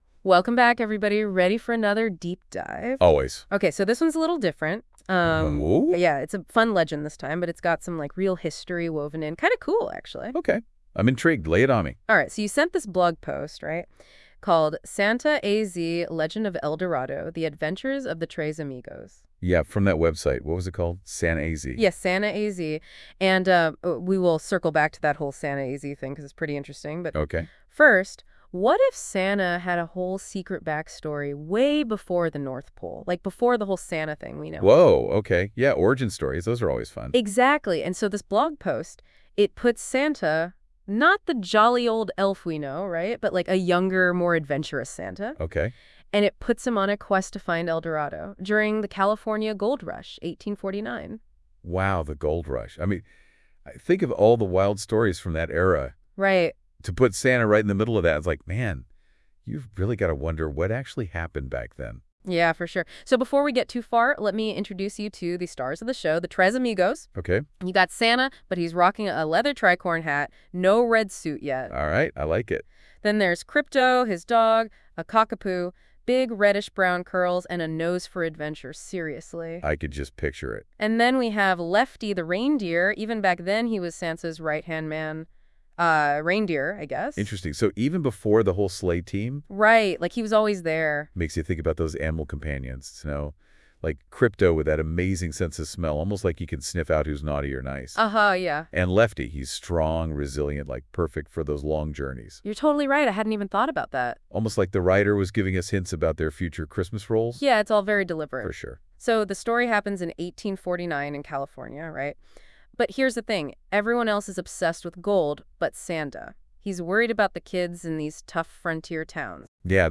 Below is a Deep-Dive audio interview on the Legend that is Santa Claus…